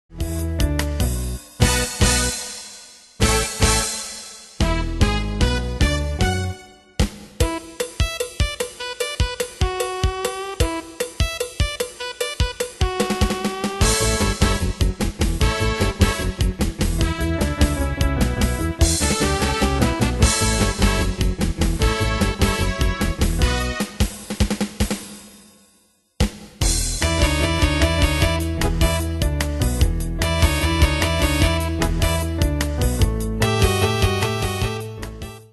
Style: Oldies Ane/Year: 1965 Tempo: 150 Durée/Time: 2.49
Danse/Dance: Soul Cat Id.
Pro Backing Tracks